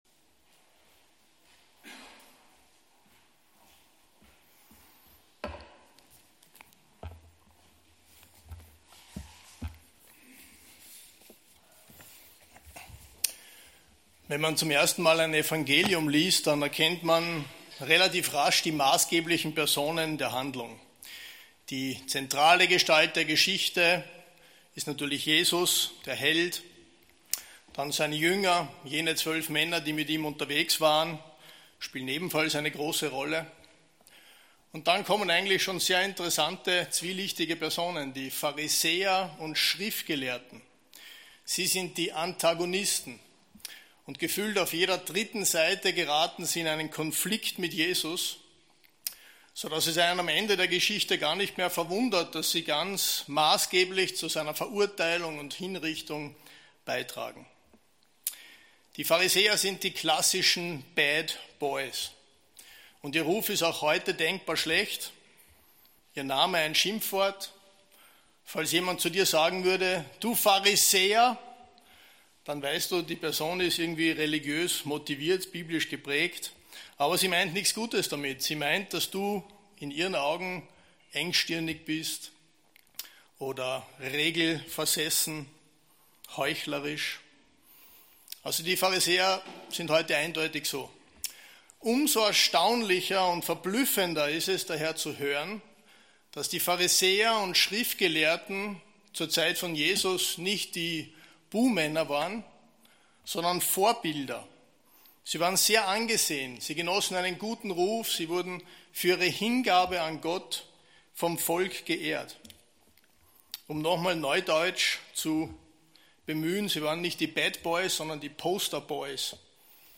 Predigtreihe: Lukas